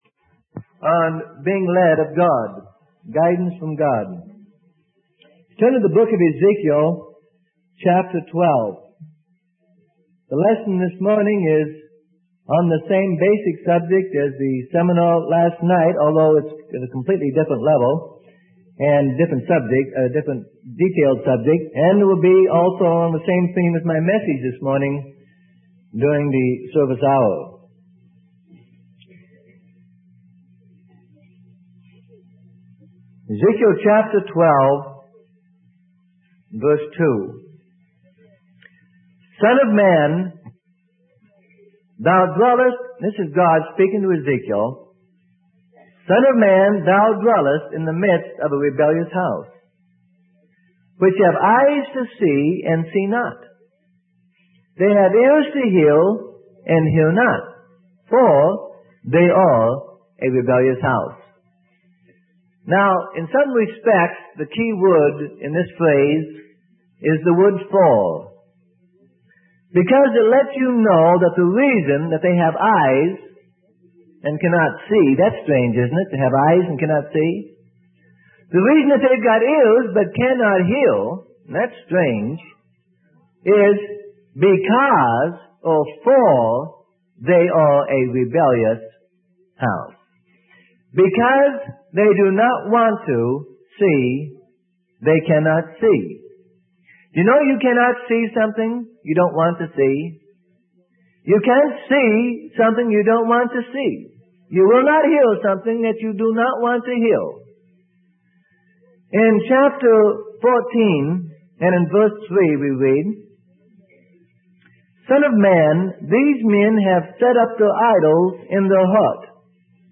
Sermon: Guidance From God - Part 04 - Freely Given Online Library